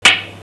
bouncehard4.wav